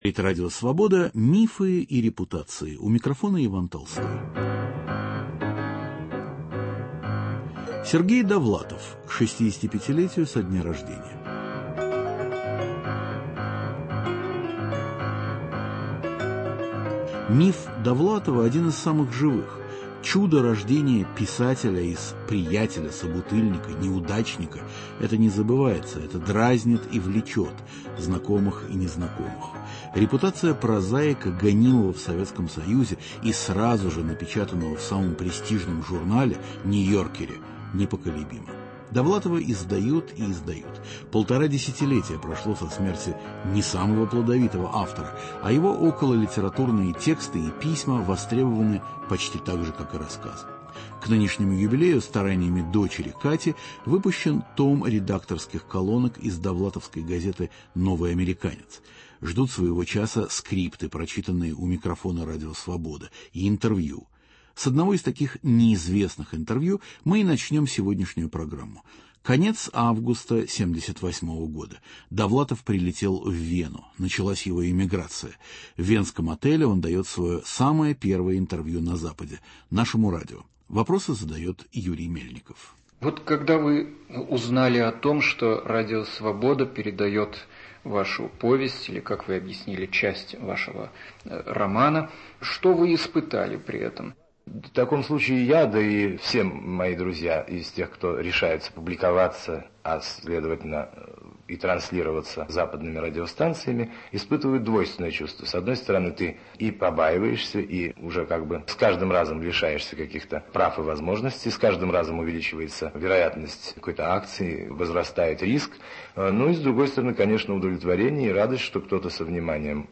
Друзья и читатели размышляют о феномене популярности, о том, какая судьба была уготована писателю, останься он жив. В программе звучит самое первое интервью, данное Довлатовым сразу по приезде на Запад в 1978 году, - из архива Радио Свобода.